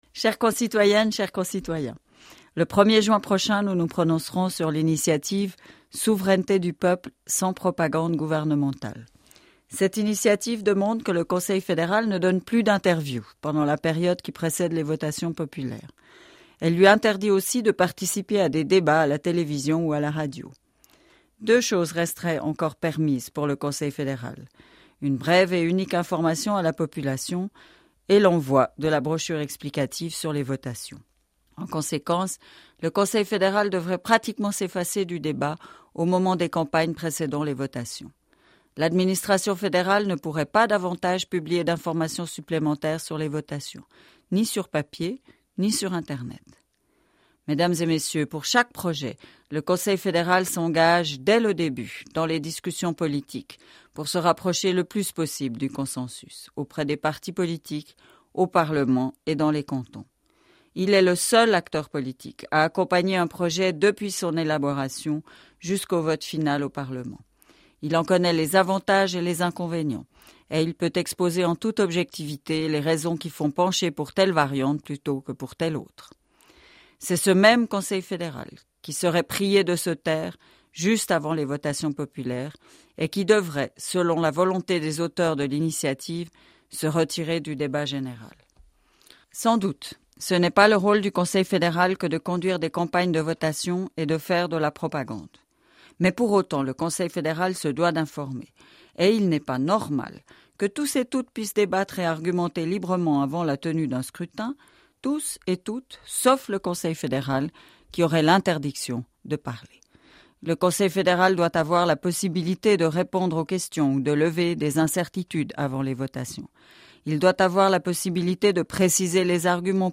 Conseillère fédérale Micheline Calmy-Rey